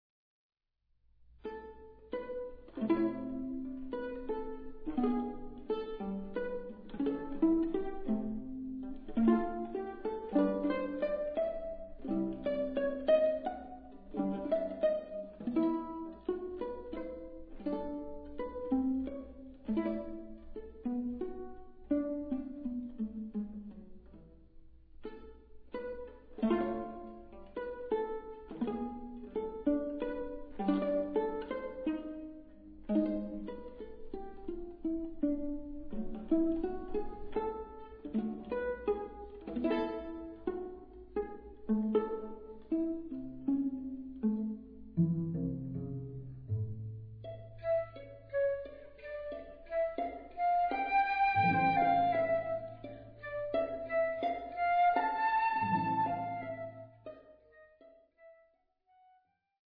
Classical
flutes
violin
viola
cello
& nbsp; flute, violin, viola & cello